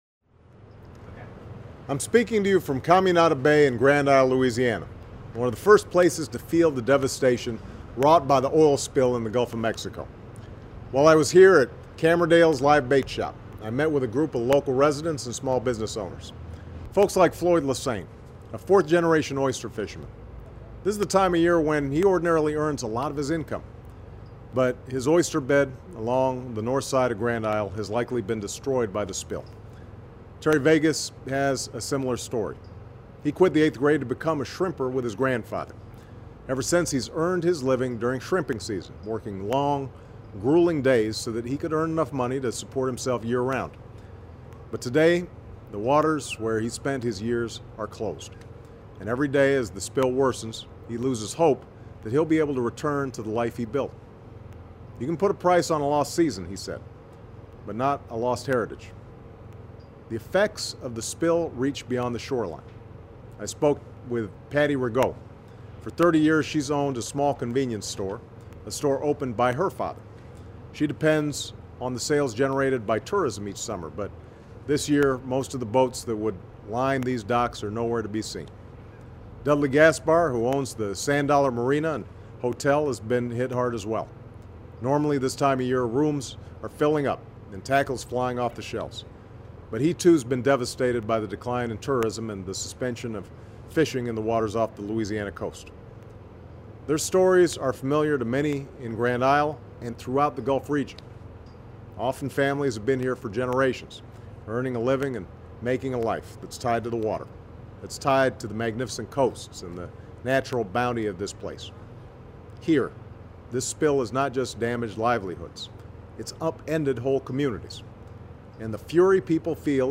Remarks of President Barack Obama
Weekly Address
Grand Isle, Louisiana